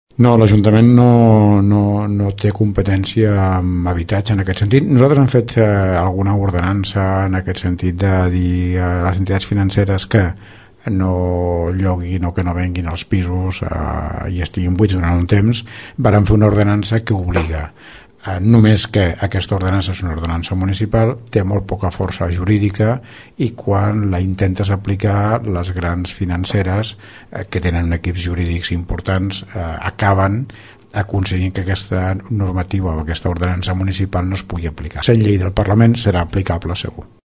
No obstant això, la legislació facilitarà l’aplicació de les ordenances municipals que obliguen a les entitats financeres i als grans propietaris a no tenir pisos buits. Escoltem l’Alcalde de Tordera, Joan Carles Garcia.